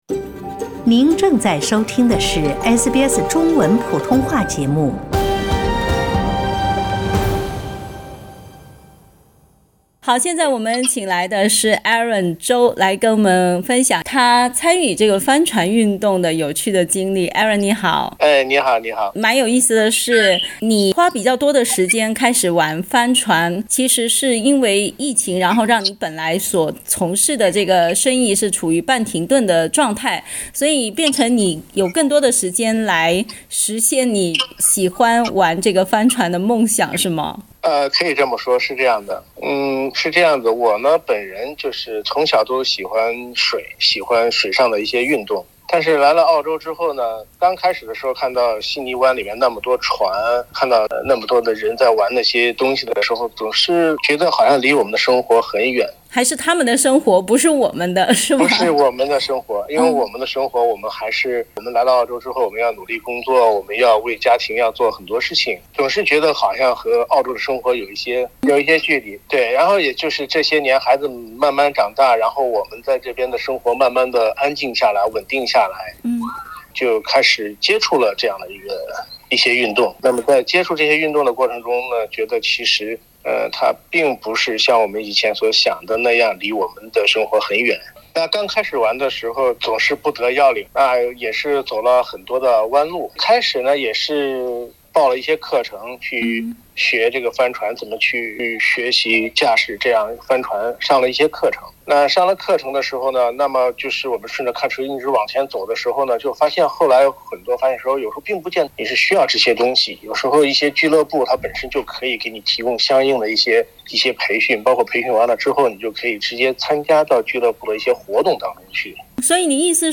（點擊圖片收聽完整寀訪）